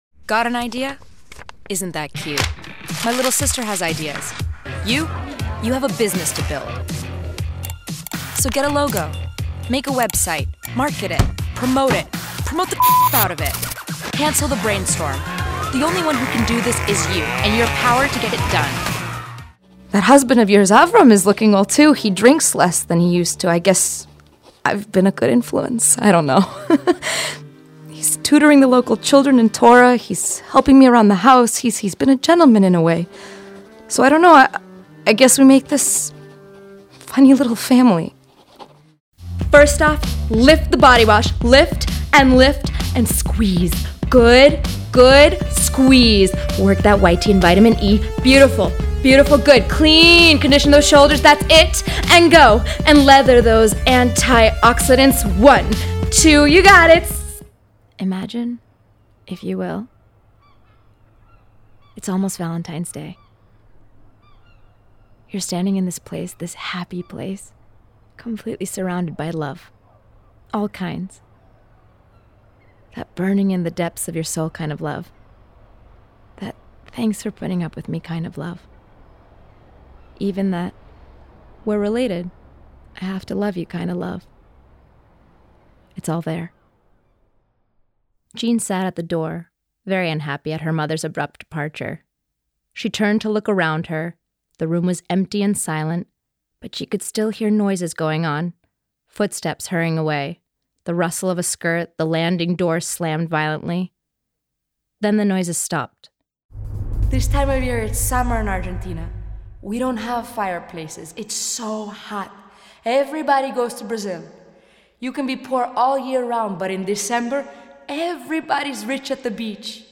Comédienne